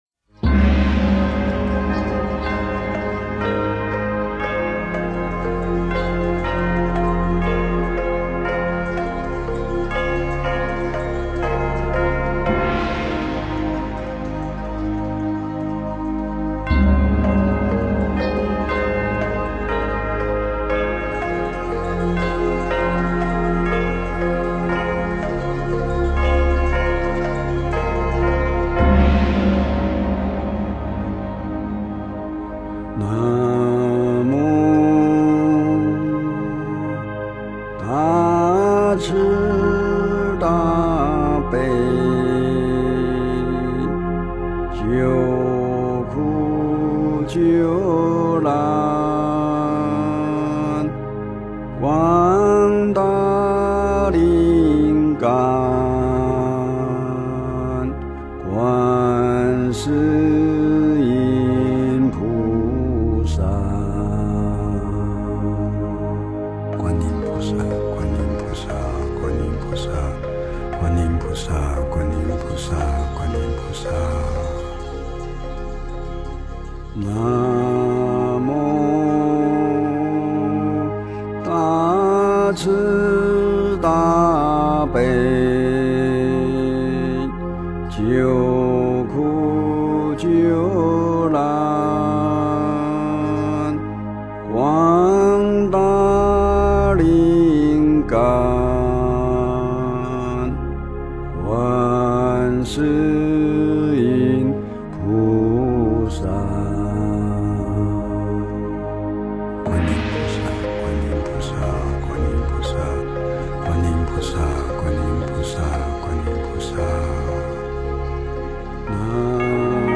诵经
佛音 诵经 佛教音乐 返回列表 上一篇： 消灾延寿药师佛解冤释结咒 下一篇： 忏悔文(法会修持版